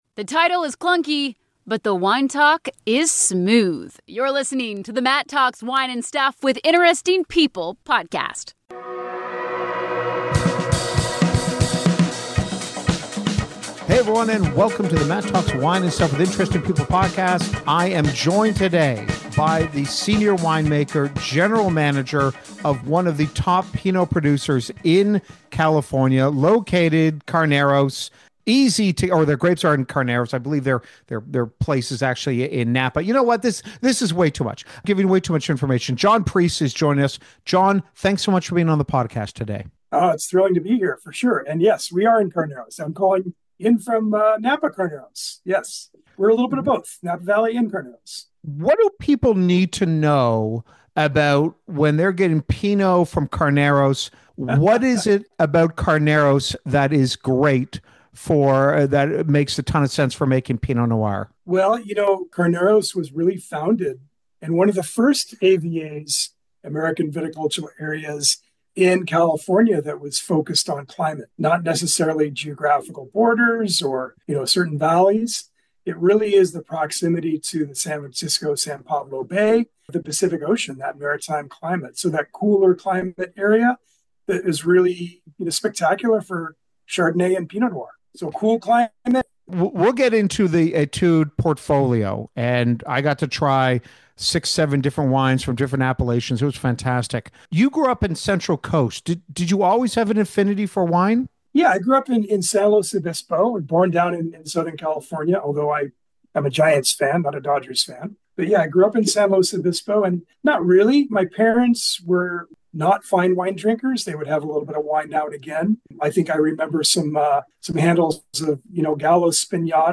In the spirit of ‘Comedians in Cars Getting Coffee’ this podcast is a biographical style interview with interesting people that will focus on their love/hate/curious relationship with wine and the fascinating lives they’ve lived so far. Oh and plenty of wine tips and recommendations.